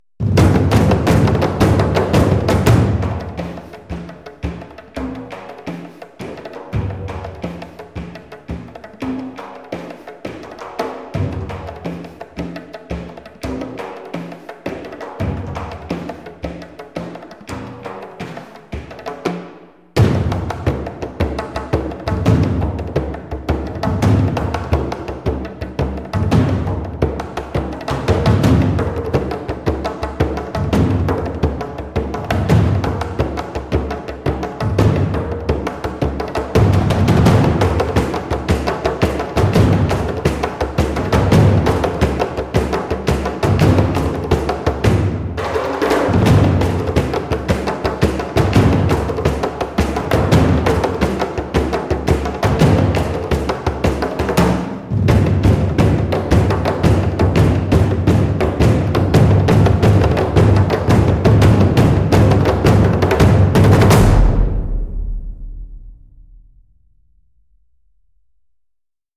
Strezov Sampling Frame Drum X3M 是一款专业的打击乐采样库，它提供了多种不同的框鼓乐器，以及多人合奏的录音。
它适合用于制作中东风格的音乐，特别是电影和视频游戏。
- 深度采样了框鼓的各种演奏技巧，包括刷子、滑动、弗拉姆和三连音
- 三种不同的录音模式：单人、三人和六人合奏
- 多种不同大小的框鼓乐器可供选择，从5英寸到15英寸
- 多种麦克风位置和效果可供调整，包括放大器、饱和度、音高偏移和释放控制